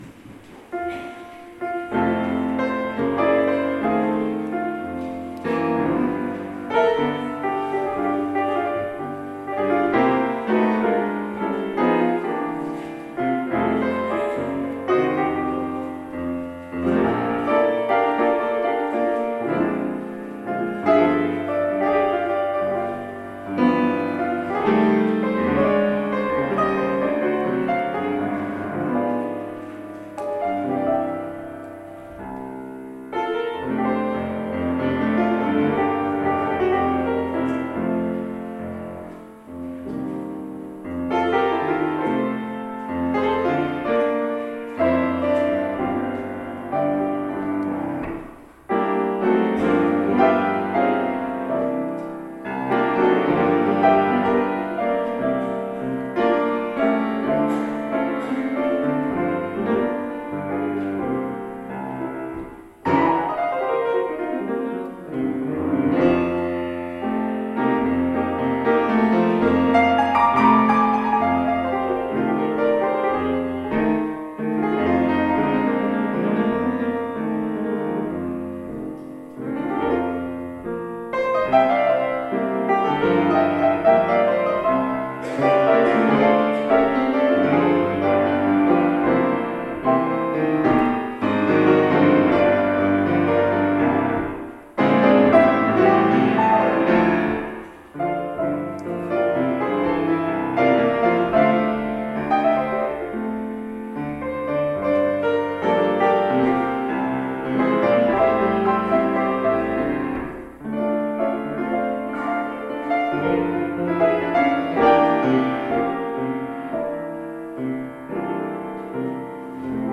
[Fuusm-l] Some music from past services